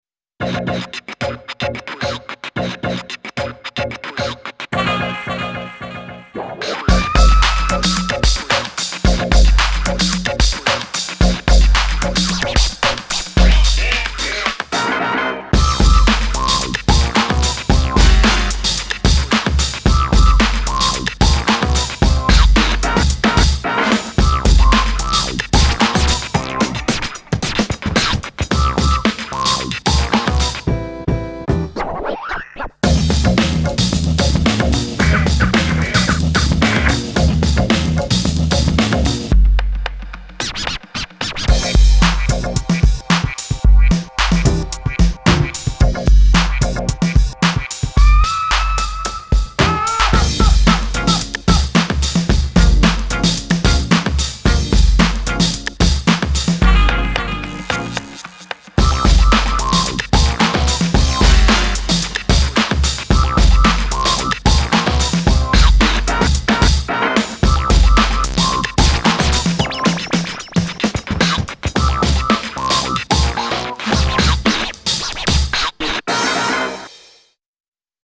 BPM111
Audio QualityPerfect (High Quality)
Genre: ELECTRONICA.